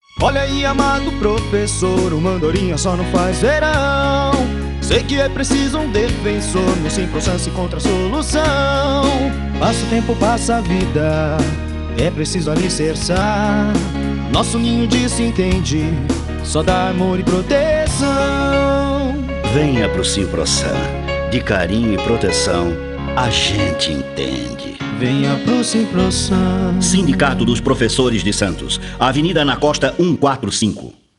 Sua voz é uma das mais versáteis da dublagem brasileira, pois ele possui quatro ou mais tipos de vozes diferentes, desde a fanhosa até seu famoso vozeirão profundo.